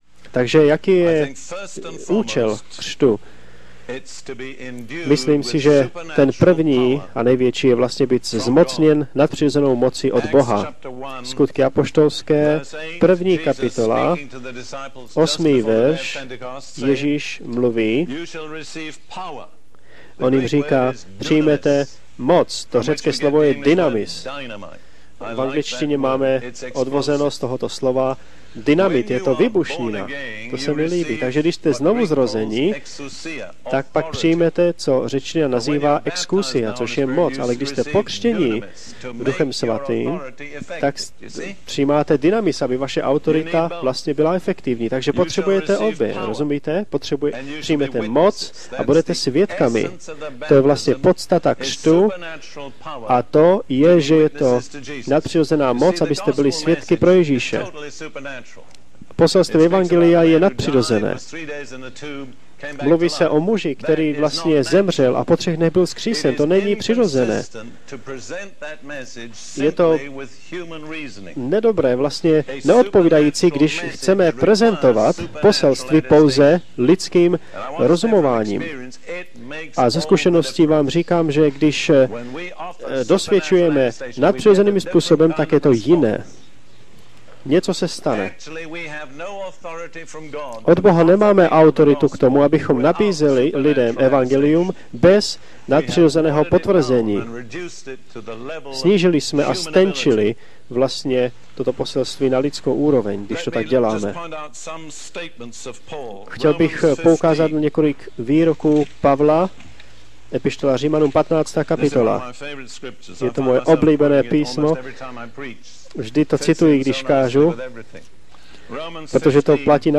Z rádiového vysílání Dereka Prince s překladem do češtiny – Ponoření do Ducha svatého